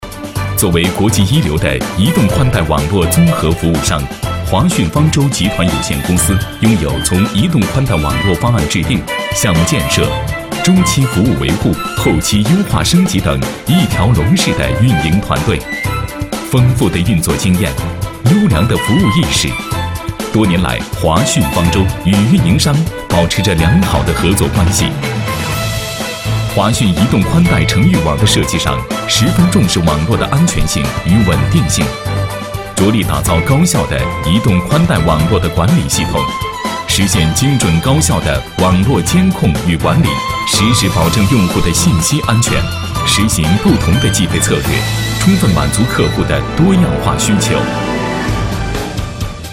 科技感 电子科技宣传片配音
大气高端，品质男音，擅长现在科技感配音，地产品质配音，宣传片配音，旁白等。